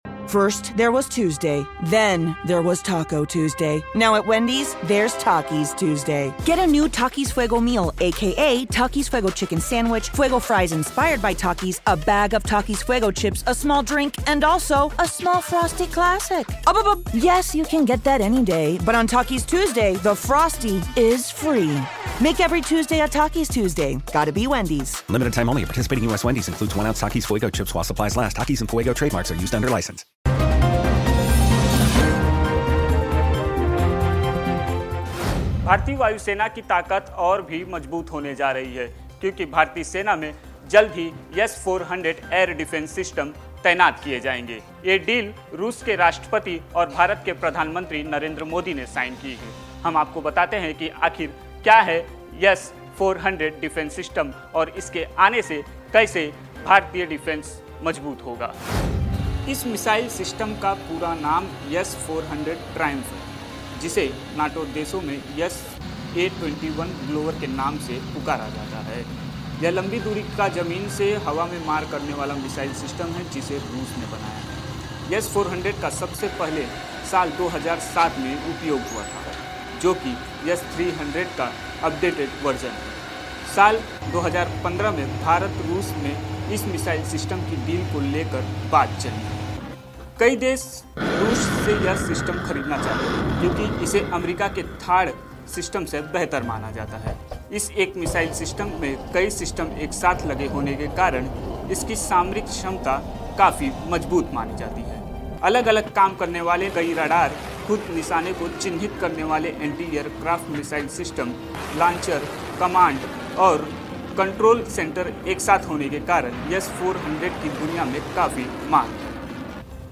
न्यूज़ रिपोर्ट - News Report Hindi / दुश्मन के उड़े होश !